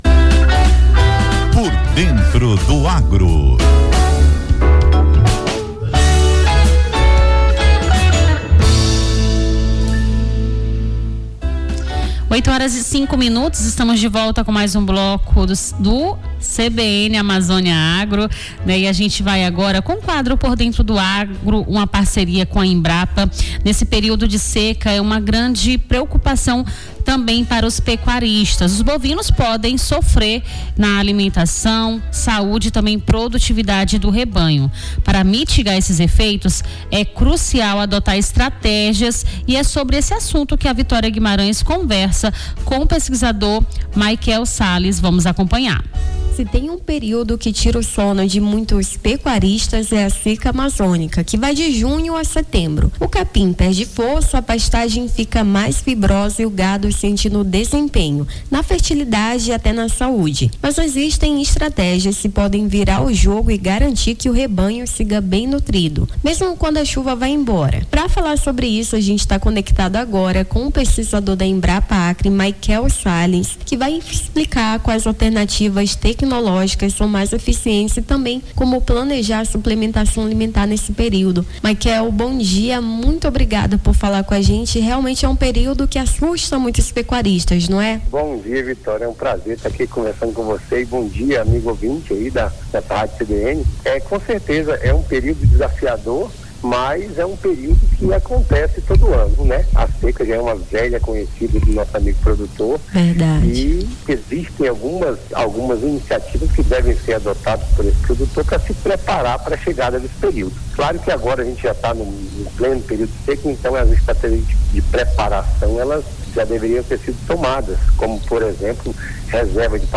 a apresentadora
conversou com o pesquisador